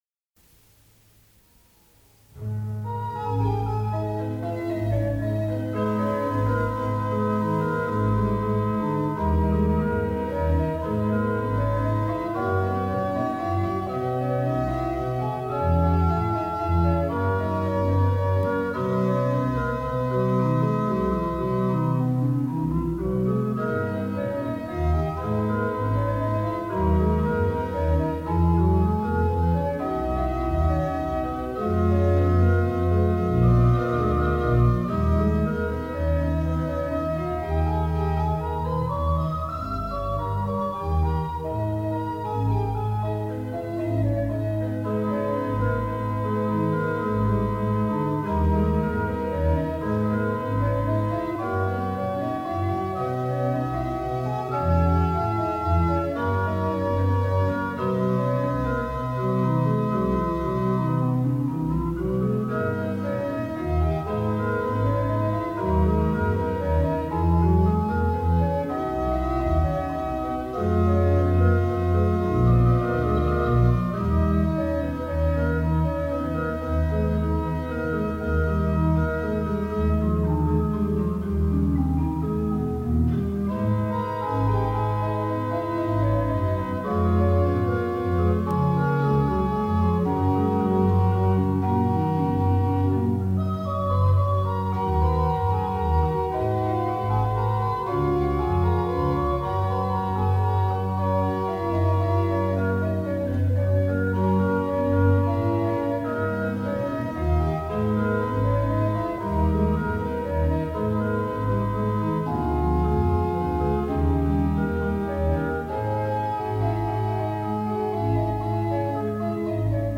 Choral
(Tempérament Werckmeister IV).